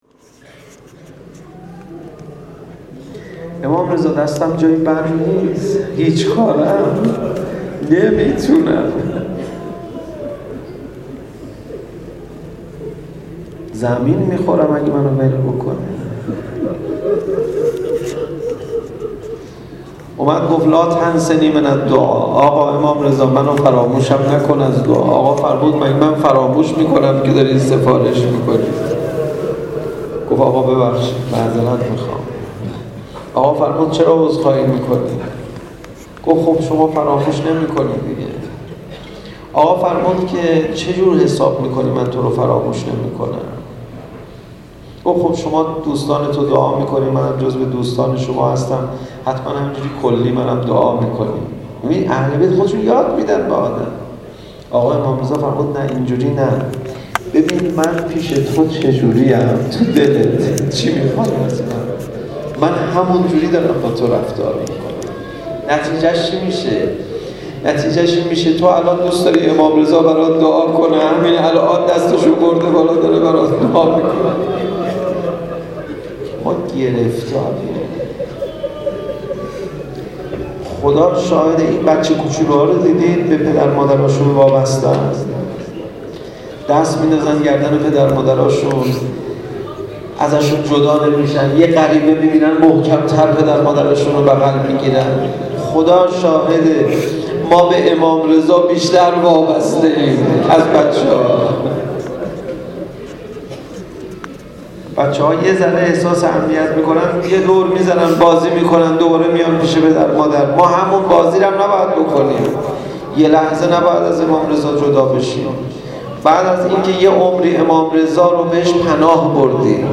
عقیق: مراسم دهه پایانی ماه صفر در مسجد امام حسین(ع) برگزار شد.